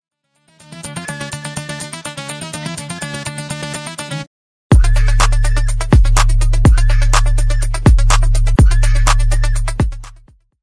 DEMO INSTRUMENTAL HERE: